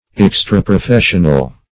Search Result for " extraprofessional" : The Collaborative International Dictionary of English v.0.48: Extraprofessional \Ex`tra*pro*fes"sion*al\, a. Foreign to a profession; not within the ordinary limits of professional duty or business.